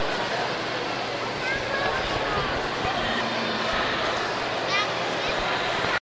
描述：在麦克风因连接松动而产生的令人敬畏的噪音之间发现的声音片段，人眼是看不到的...